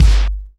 CLAPKICK.wav